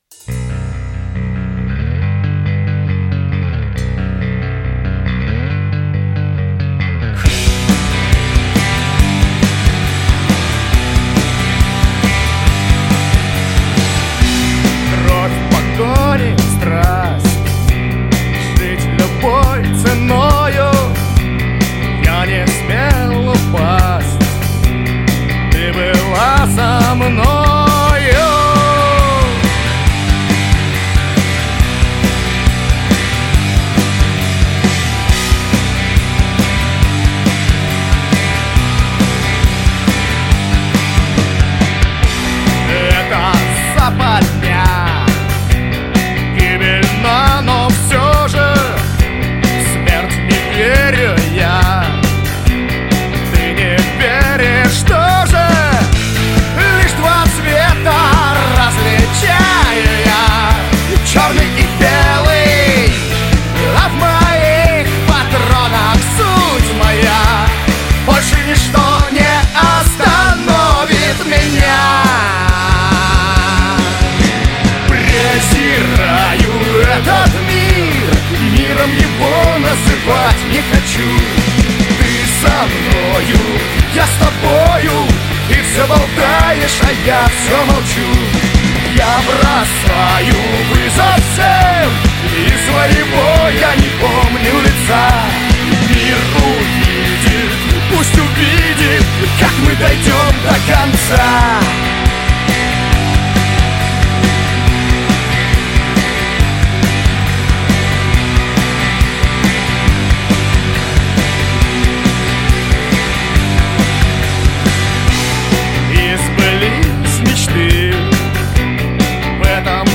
Жанр: Жанры / Рок